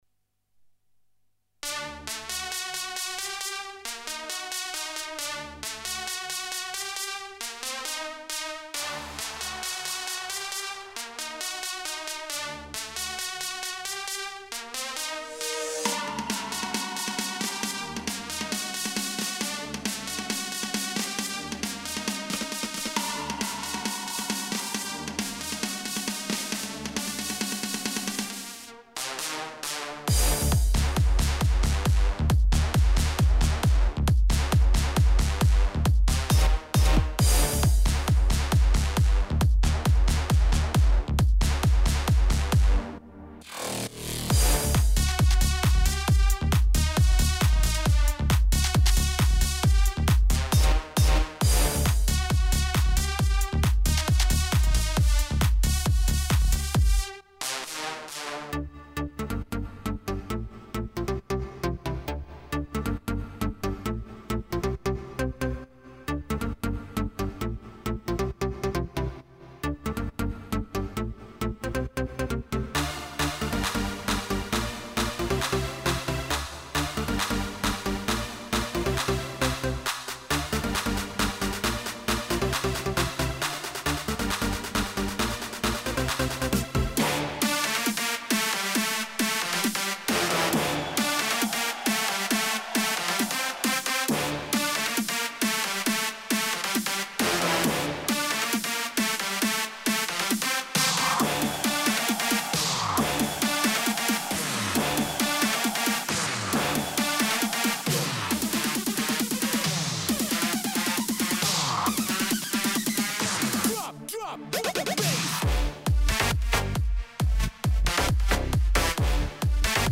טראק אורגן קורג